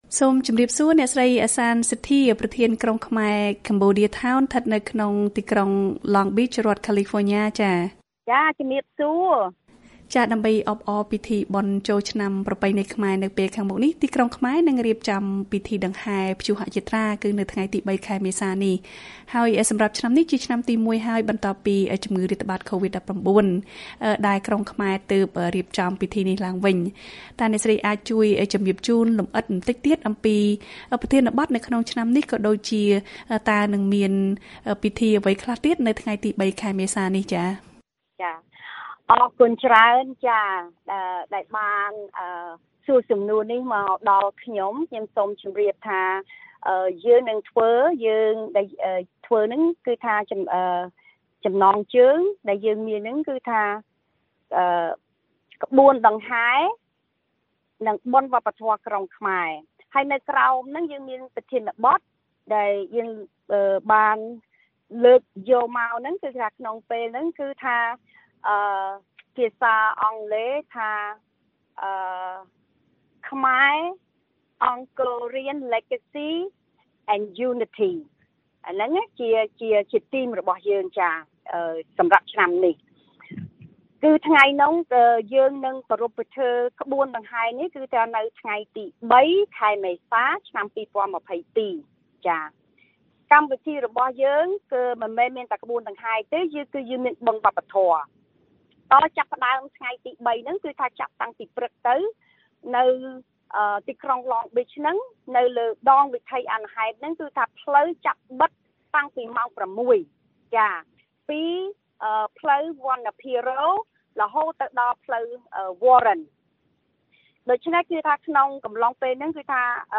បទសម្ភាសន៍ VOA៖ ពិធីក្បួនដង្ហែ និងបុណ្យវប្បធម៌ក្រុងខ្មែរ